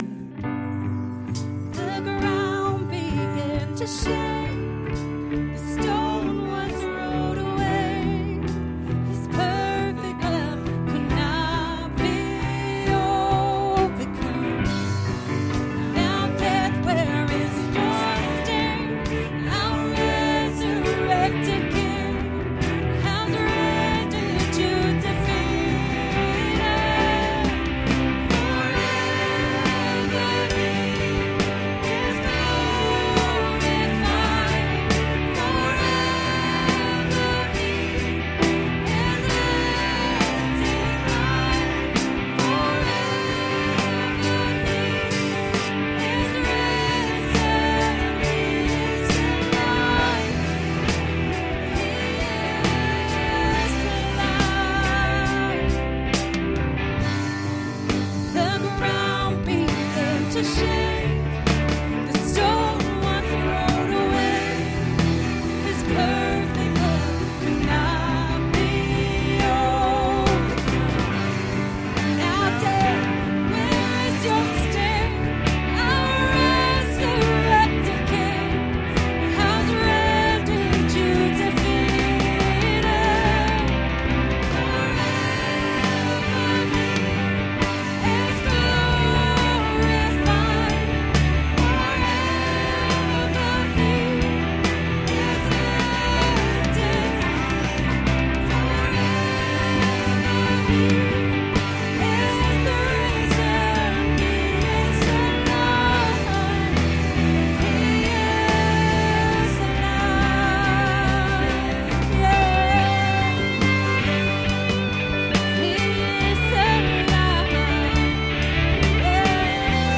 18Oct'15_Healing Service.mp3